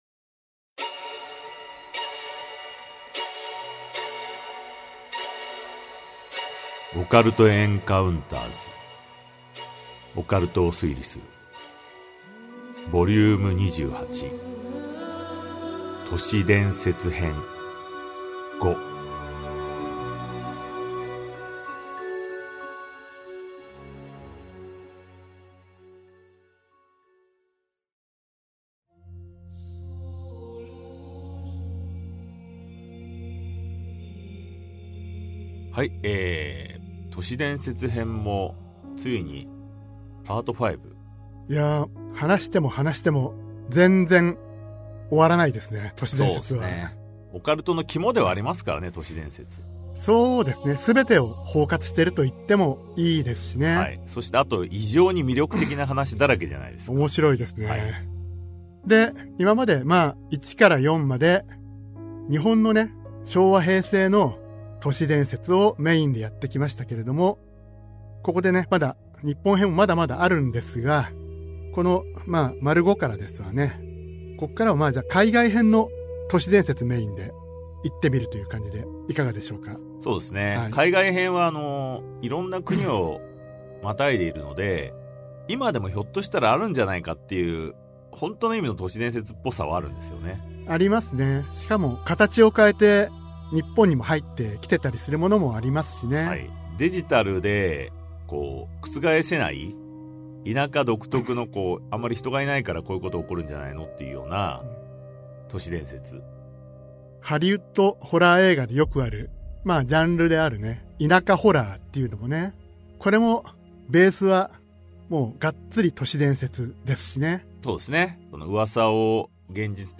[オーディオブック] オカルト・エンカウンターズ オカルトを推理する Vol.28 都市伝説 5
オカルト・エンカウンターズの二人が資料と証言を紐解き、闇に埋もれた真相を推理する──。